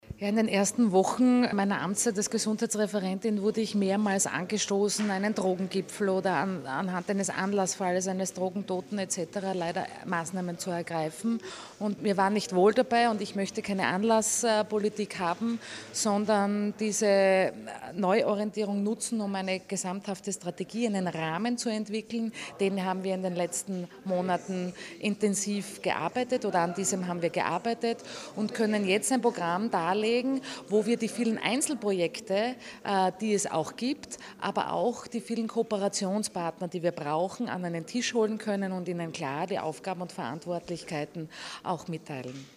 O-Ton: Die neue steirische Suchtpolitik
Gesundheitslandesrätin Kristina Edlinger-Ploder: